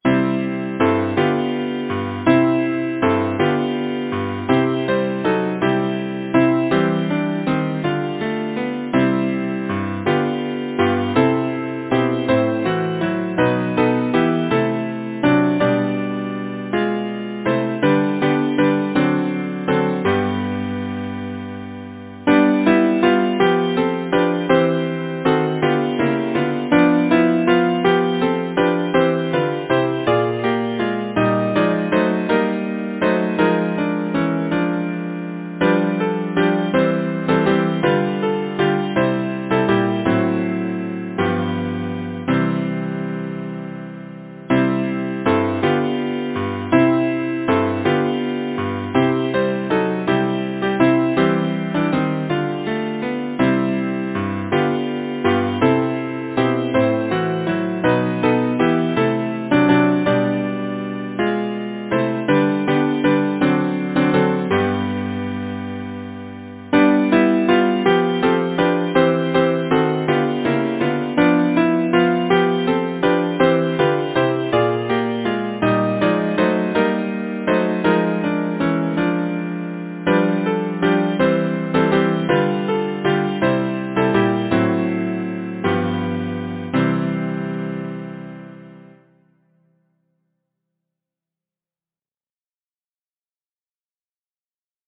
Title: Longing Composer: John Spencer Camp Lyricist: Number of voices: 4vv Voicing: SATB Genre: Secular, Partsong
Language: English Instruments: A cappella